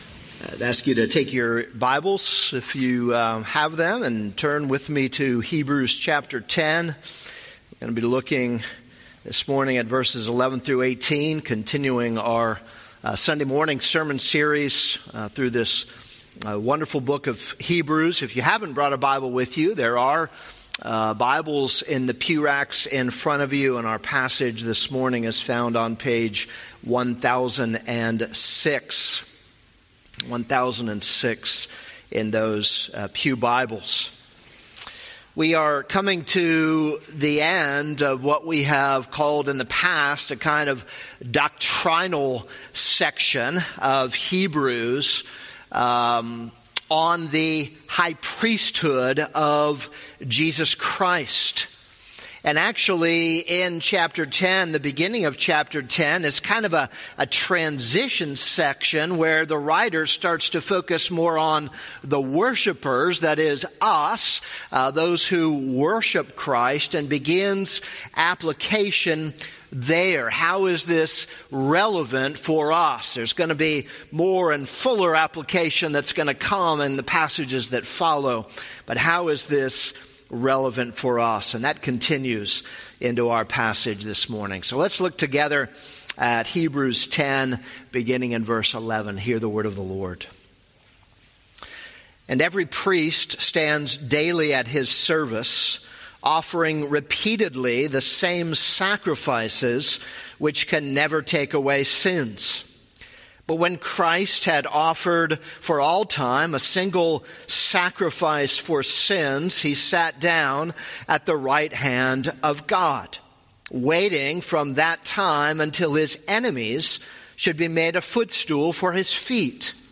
This is a sermon on Hebrews 10:11-18.